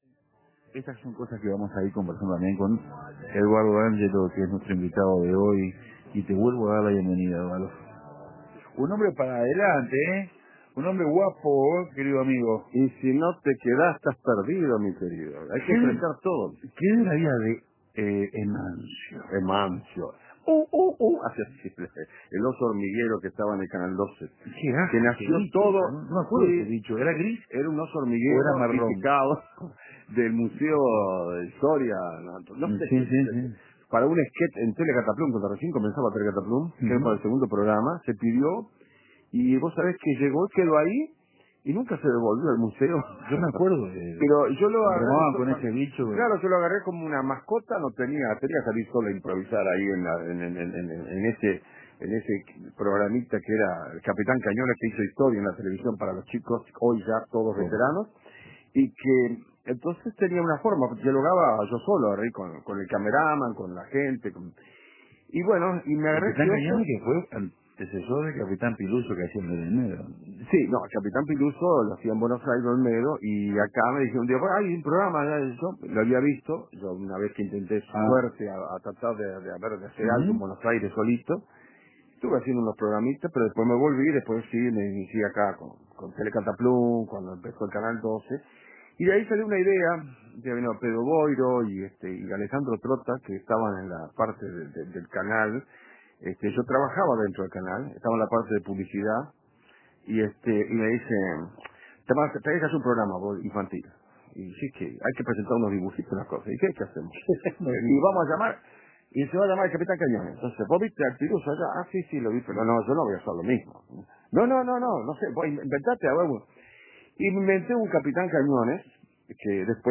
Eduardo D´Angelo llenó la fonoplatea de super héroes y más de mil voces. Entre la realidad y los sueños, un tren conducido por D´Angelo.